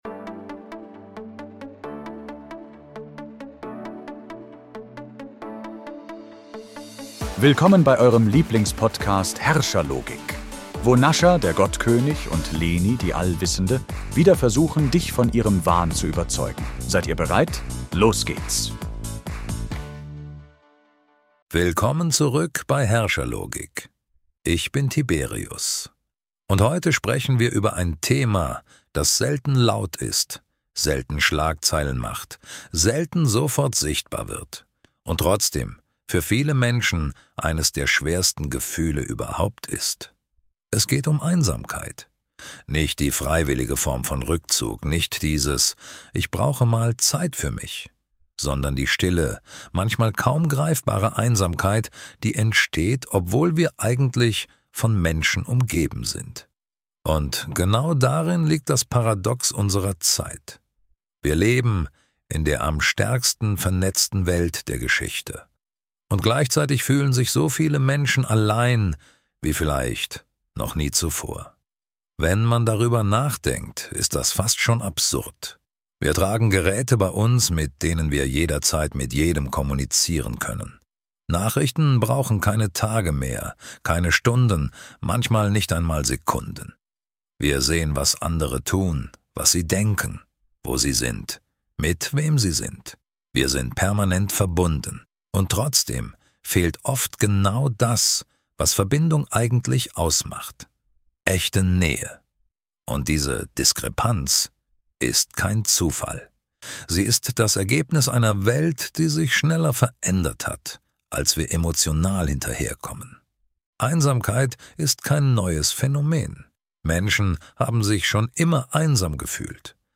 In einer ruhigen, reflektierten Solo-Folge geht es nicht um das klassische „Alleinsein“, sondern um die moderne Form der Einsamkeit – mitten im Alltag, mitten unter Menschen, mitten in einer digital vernetzten Welt.